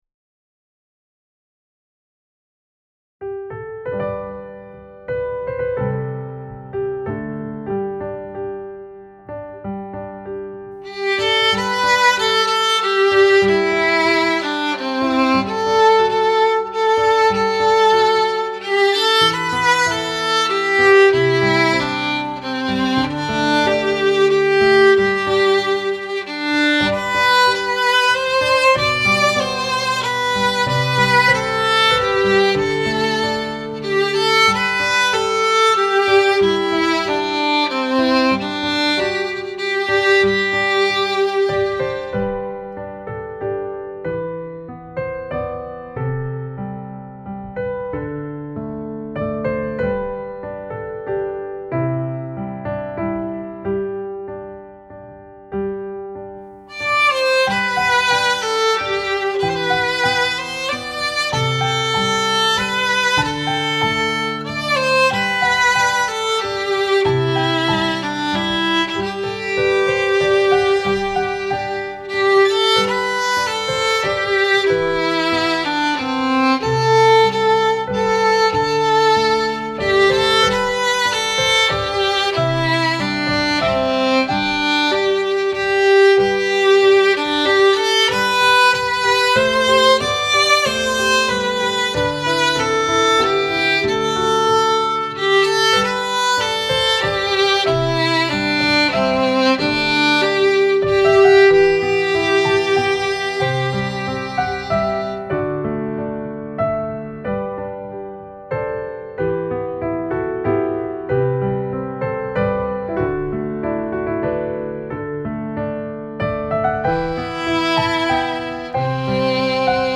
Instrumentation: Violin, Piano Accompaniment
violin solo with piano accompaniment.
is a traditional English carol.
Free Download: Violin and Piano mp3 Demo Recording